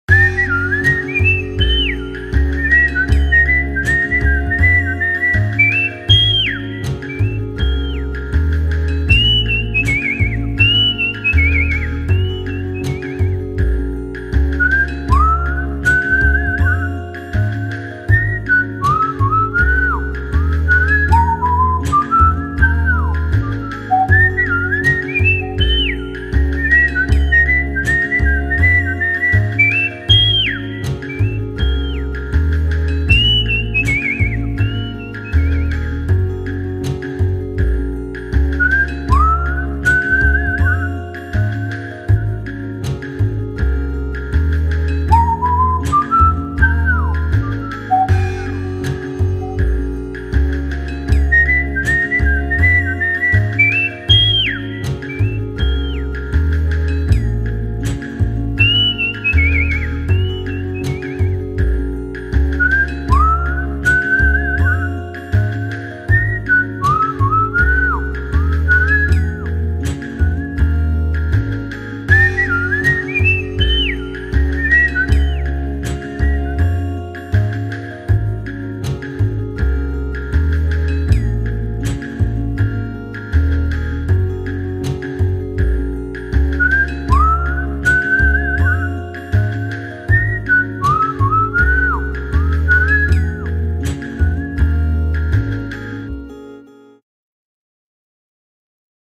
アフリカ口笛（クチコミ曲）